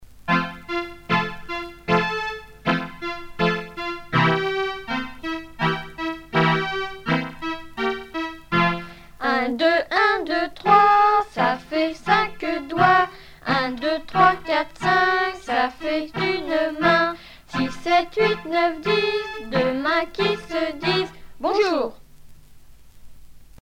formulette enfantine : jeu des doigts
Pièce musicale éditée